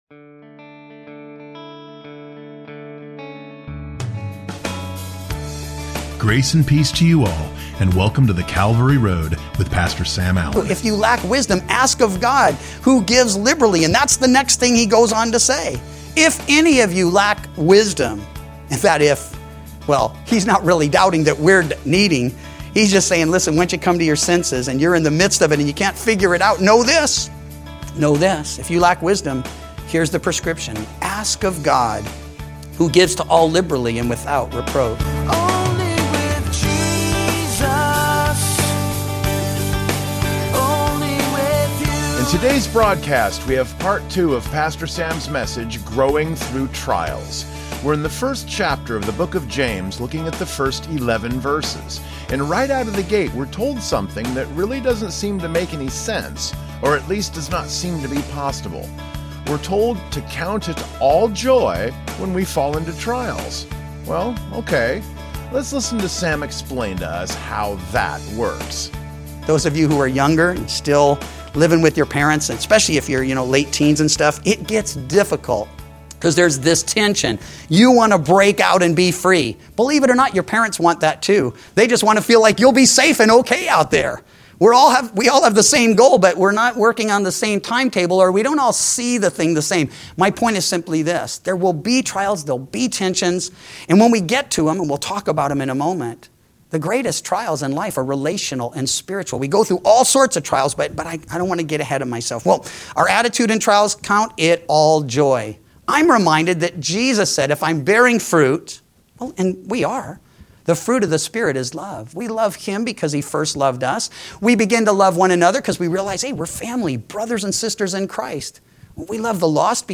Weekday Radio Program